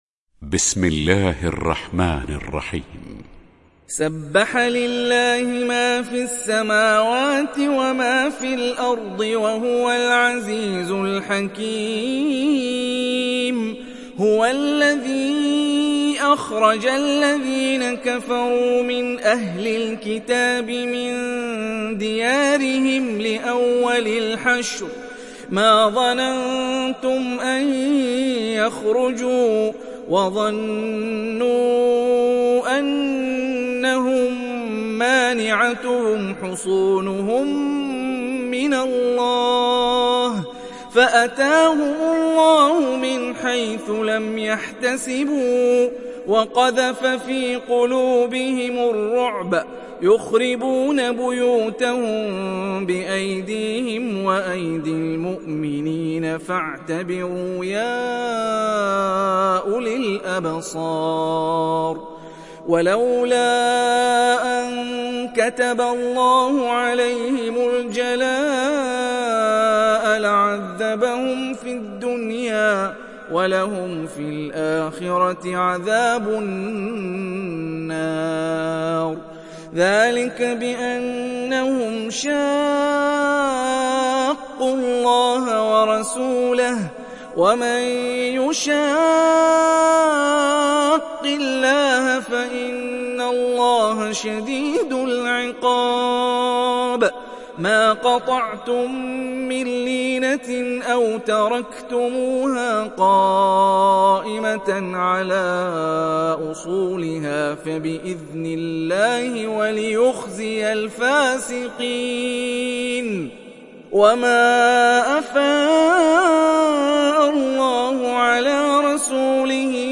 تحميل سورة الحشر mp3 بصوت هاني الرفاعي برواية حفص عن عاصم, تحميل استماع القرآن الكريم على الجوال mp3 كاملا بروابط مباشرة وسريعة